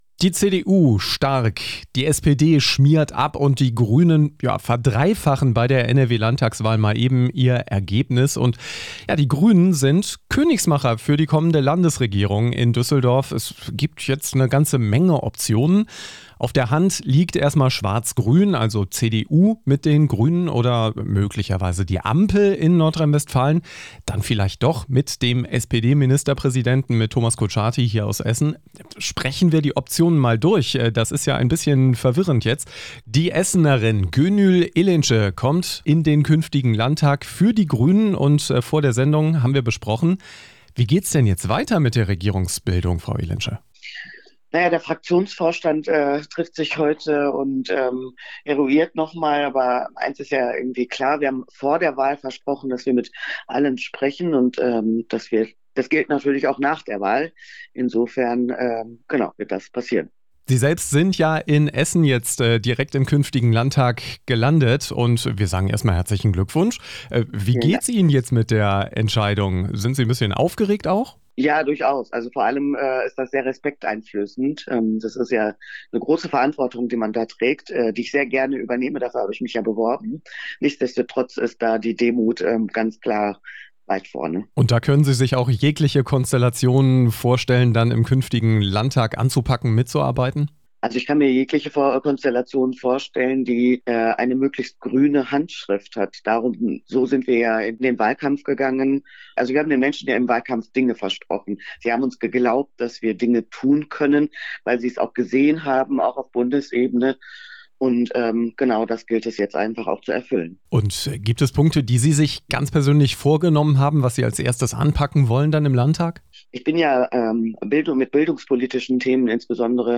Wir von Radio Essen haben mit ihr gesprochen.